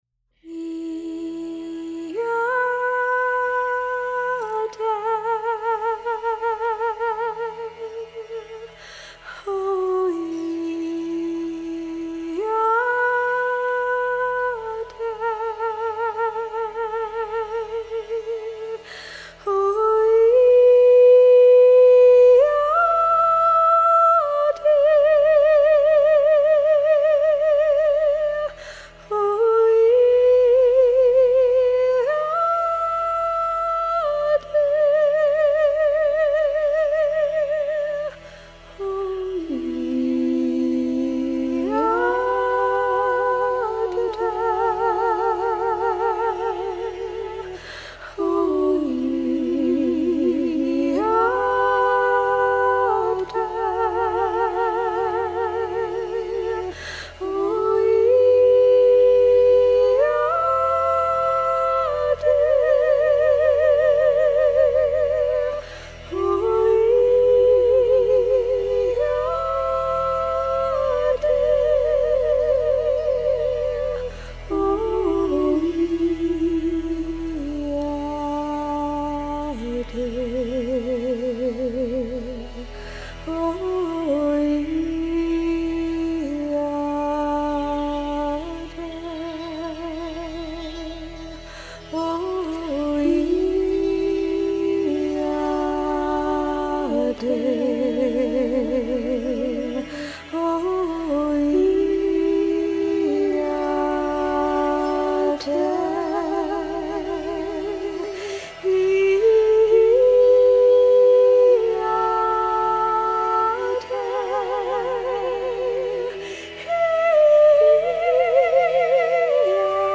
Lyrics